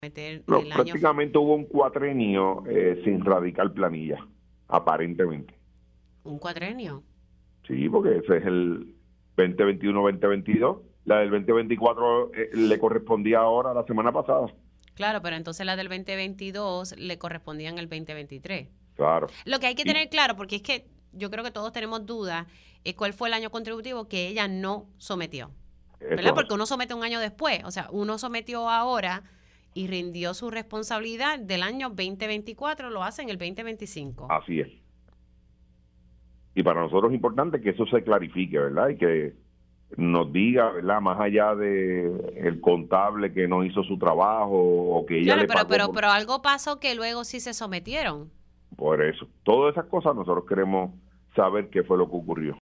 206-JUAN-OSCAR-MORALES-SENADOR-PNP-IMPORTANTE-CONOCER-QUE-AnO-FERRAIOULI-NO-RADICO-PLANILLA.mp3